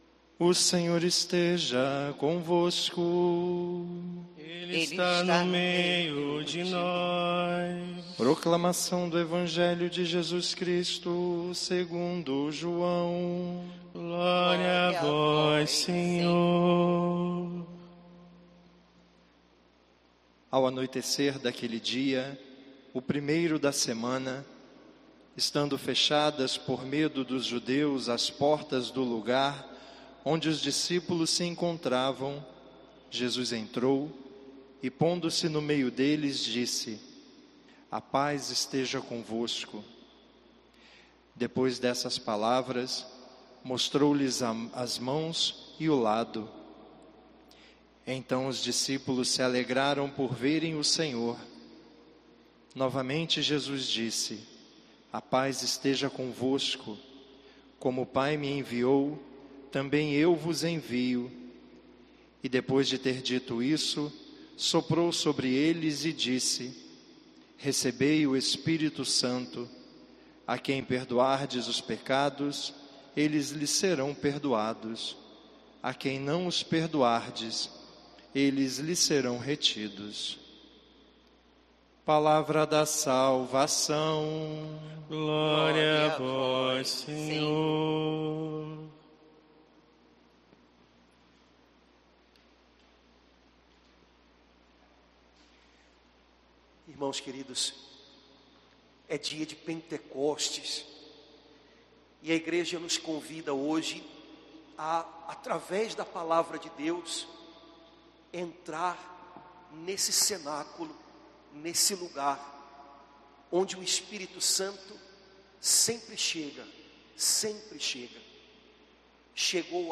Homilia 31/05/2020 – Solenidade de Pentecostes | Domingo